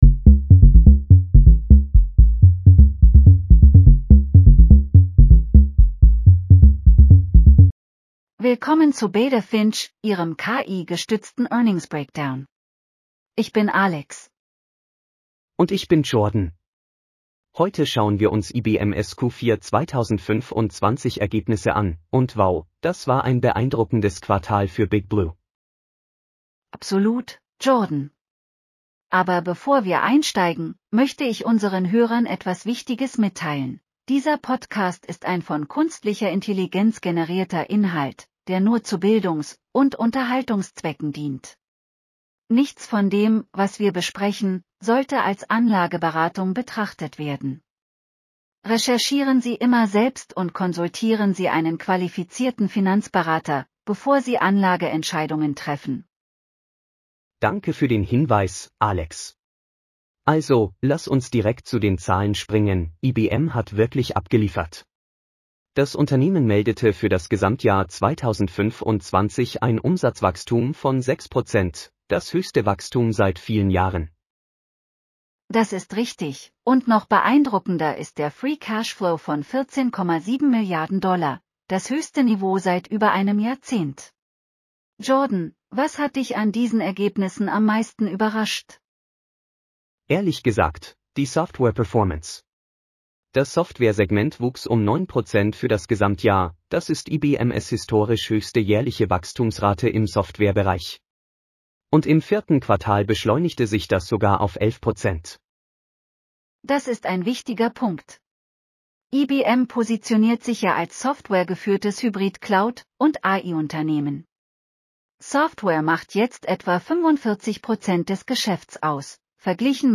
Beta Finch Podcast Script - IBM Q4 2025 Earnings